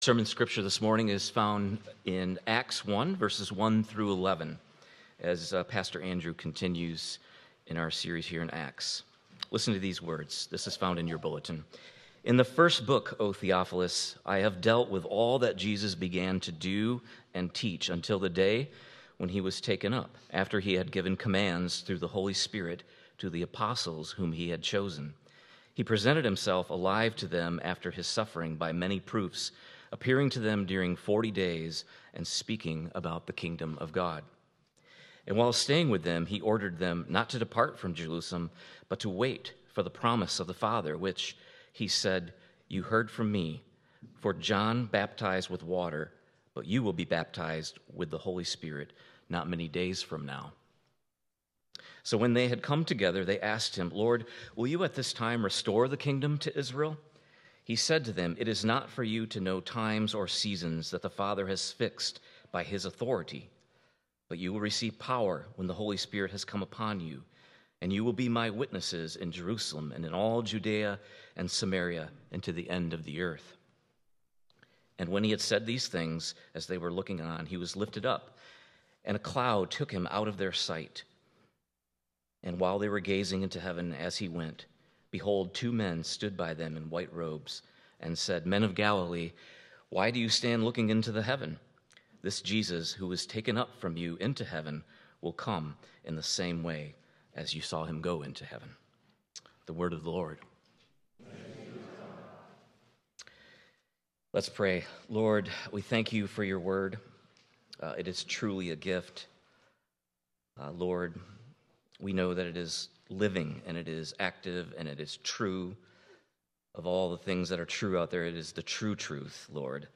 1.18.26 Sermon.m4a